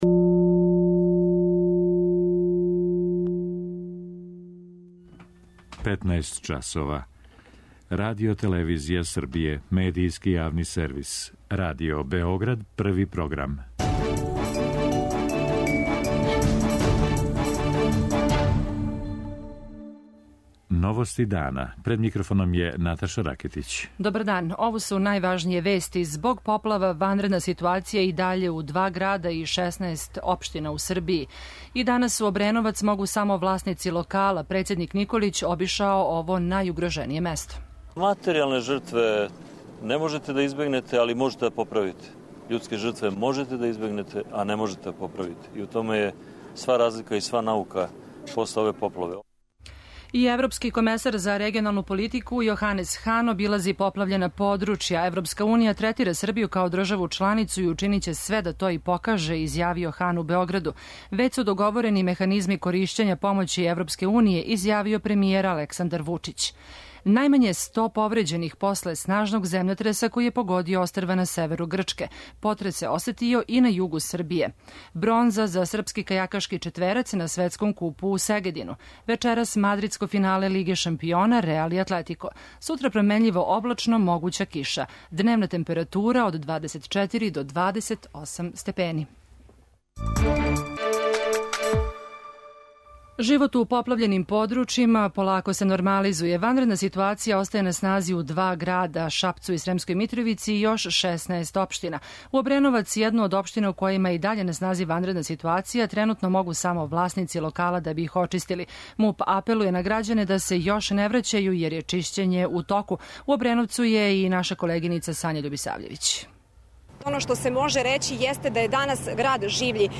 Живот у поплављеним подручјима полако се нормализује, о чему ће за Новости дана извештавати наши репортери. Ванредна ситуација остаје на снази у два града - Шапцу и Сремској Митровици - и још 16 општина.
преузми : 16.59 MB Новости дана Autor: Радио Београд 1 “Новости дана”, централна информативна емисија Првог програма Радио Београда емитује се од јесени 1958. године.